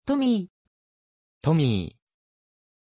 Pronuncia di Tommy
pronunciation-tommy-ja.mp3